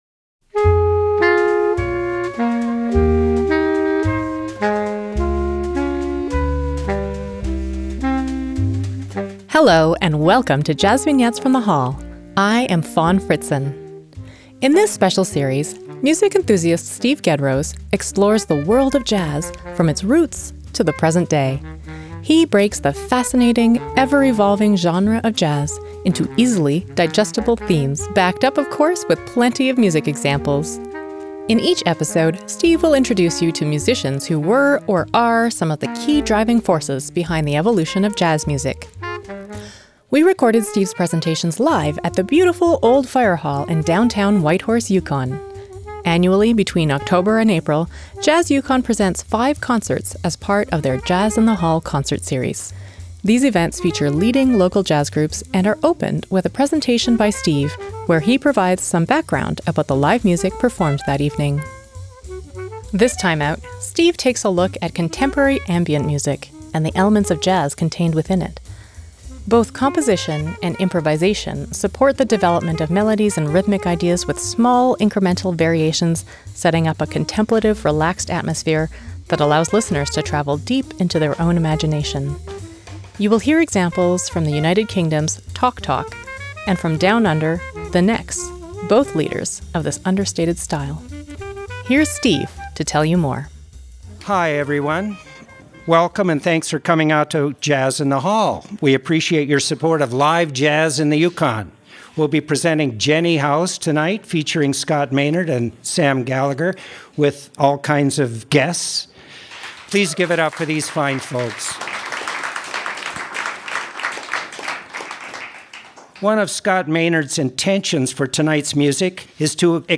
JVFTH_33_-_Ambient.mp3 57,846k 256kbps Stereo Comments
JVFTH_33_-_Ambient.mp3